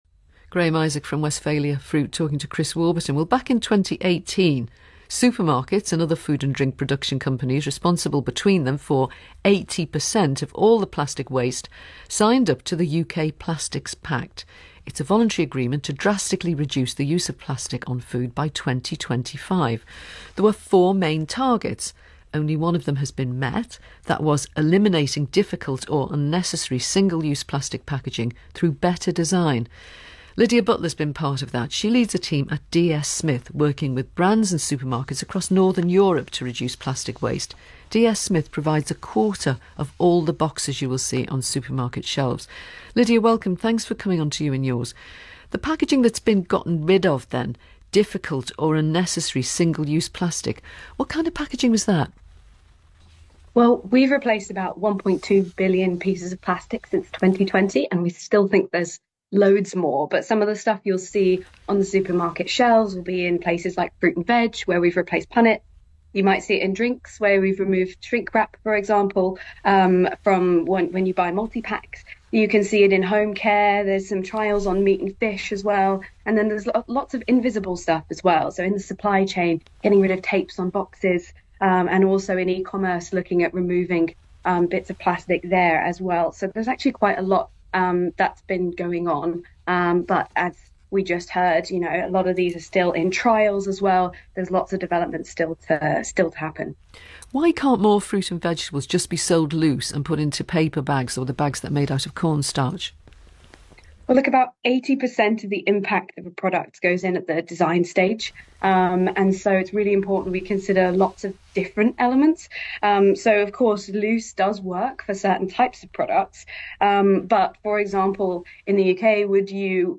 The ‘You and Yours’ programme on BBC Radio 4 hosted a riveting discussion about the ongoing changes in supermarket packaging. The conversation revolved around the urgent need to reduce single-use plastic from supermarket shelves, a topic that has gained significant attention in recent years